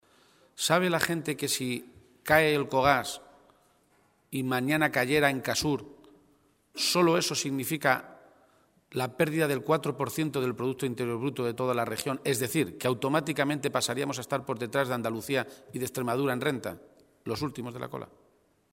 García-Page se pronunciaba de esta manera esta mañana, en Toledo, a preguntas de los medios de comunicación sobre la extraordinaria movilización vivida ayer en Puertollano contra el cierre de Elcogás, en una manifestación que congregó a más de 20.000 personas, según los sindicatos convocantes, y en la que participó también el propio líder de los socialistas castellano-manchegos.
Cortes de audio de la rueda de prensa